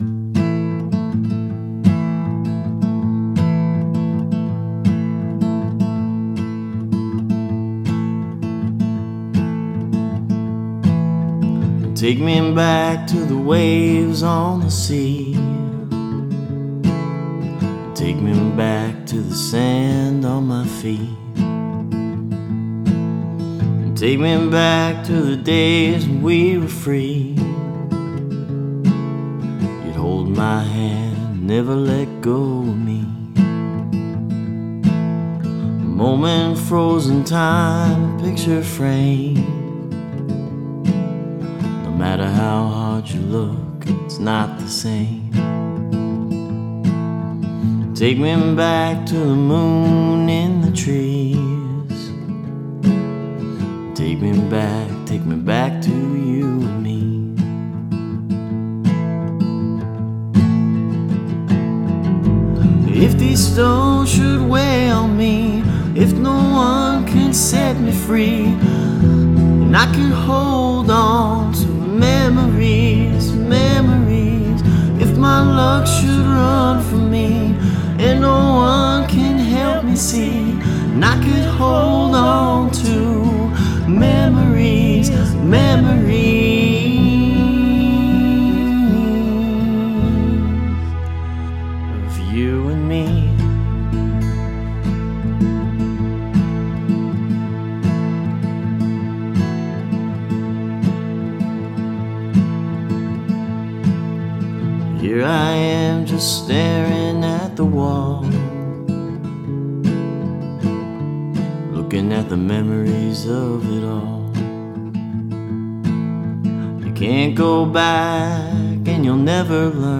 New one, very sparse.